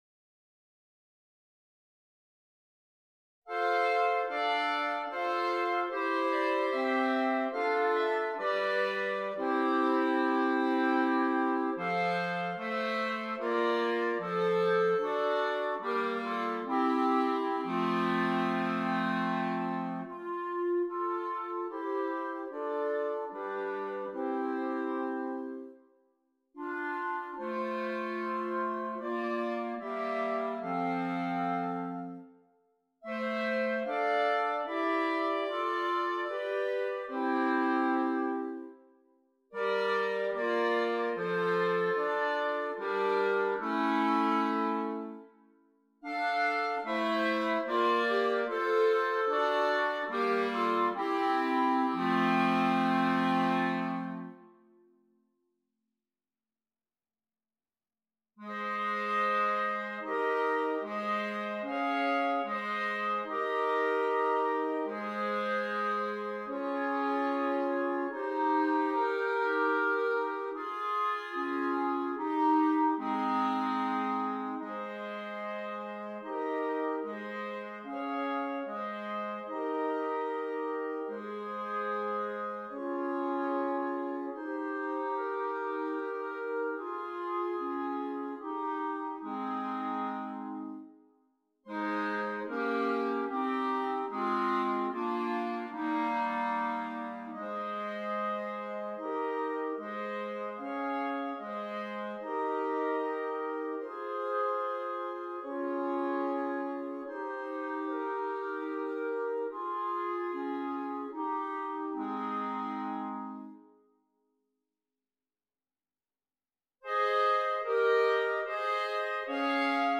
• 4 Clarinets